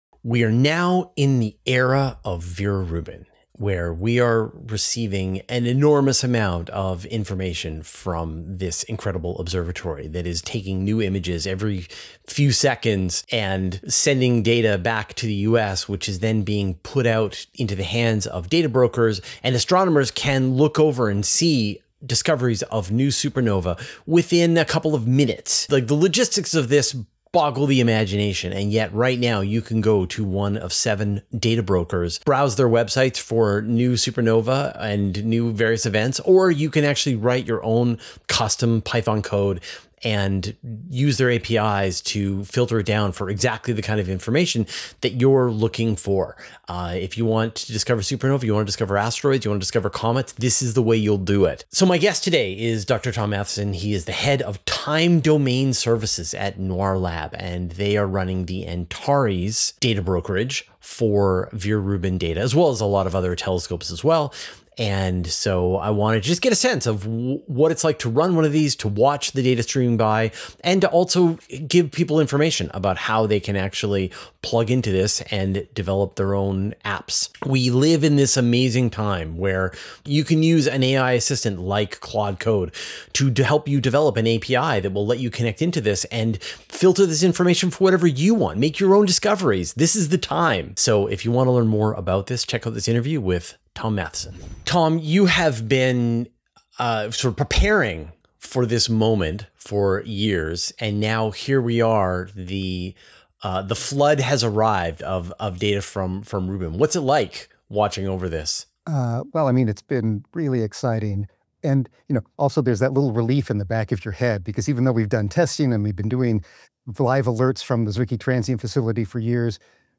Finding the answers in this interview.